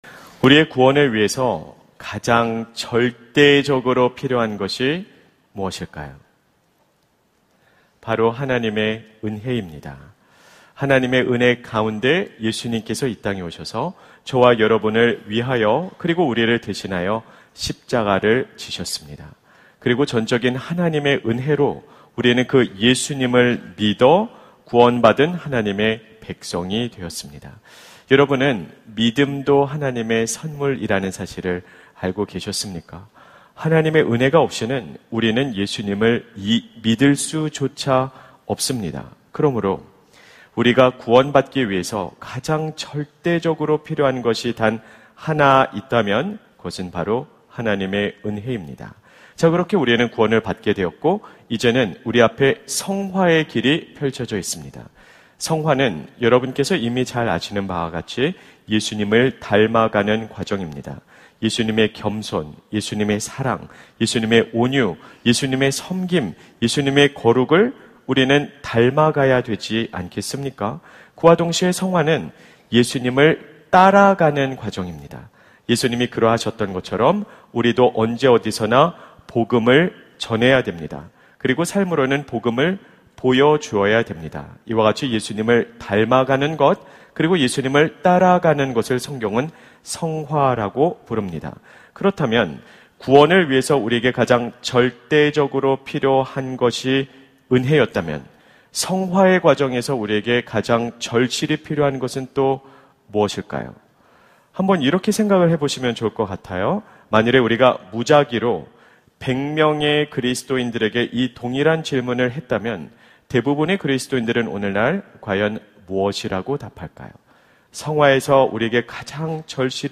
설교 : 수요향수예배